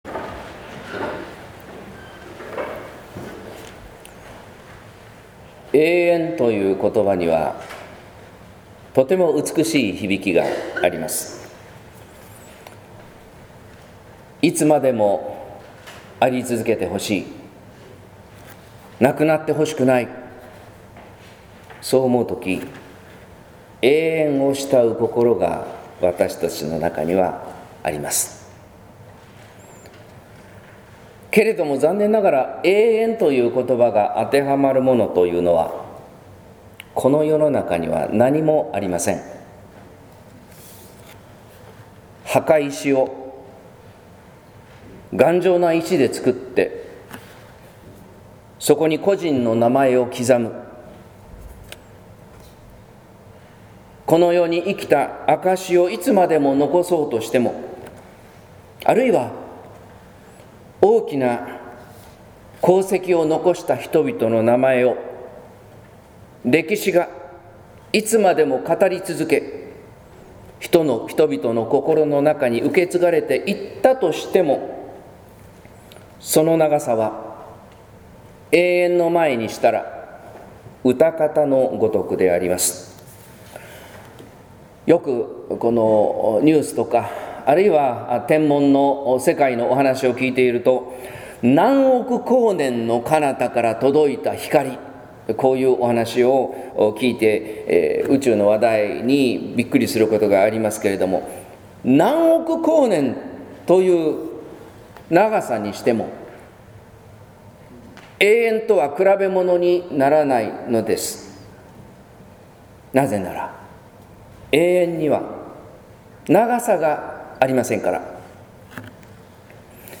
説教「人の死と神の愛」（音声版） | 日本福音ルーテル市ヶ谷教会